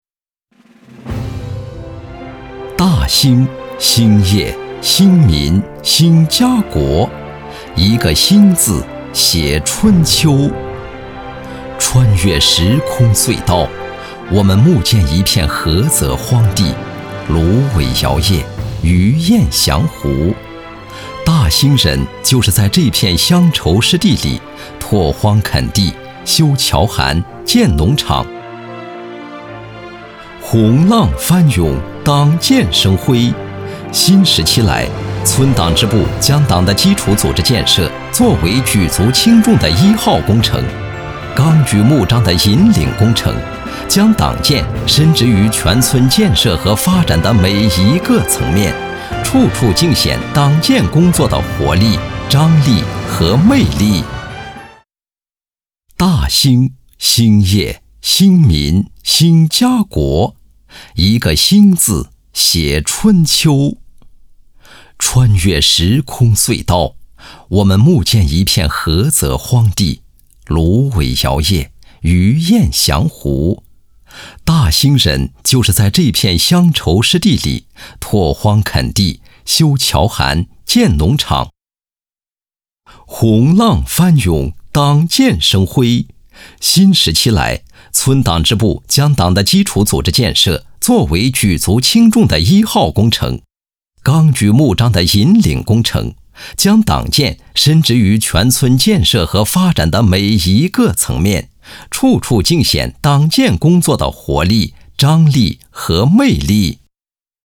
专注高端配音，拒绝ai合成声音，高端真人配音认准传音配音
男67